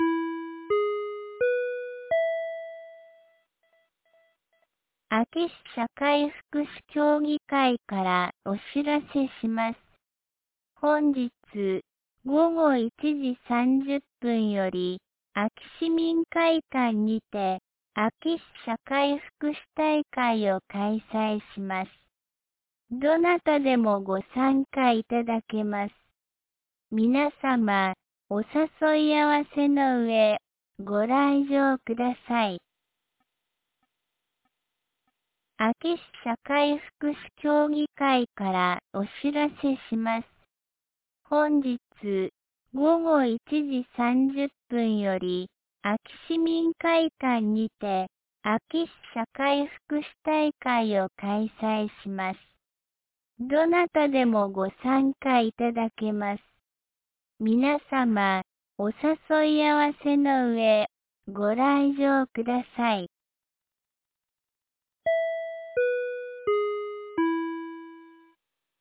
2026年01月31日 10時01分に、安芸市より全地区へ放送がありました。